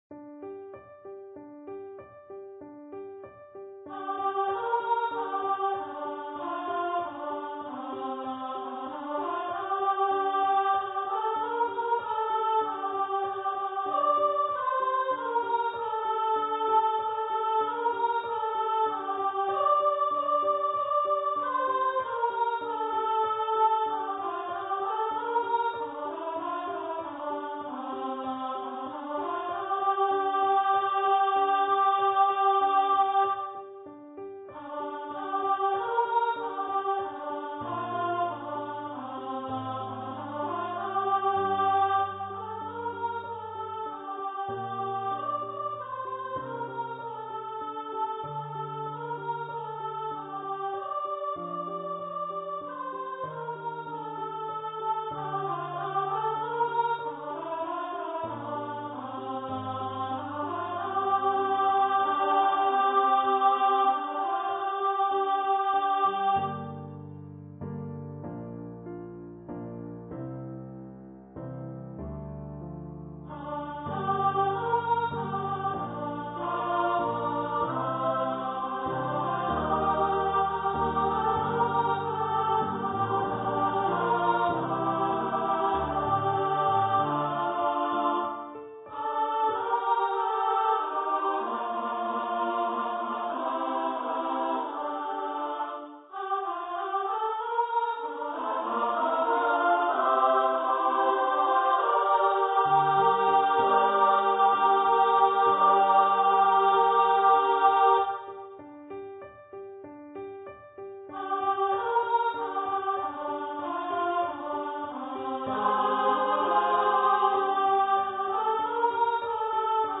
for female voice choir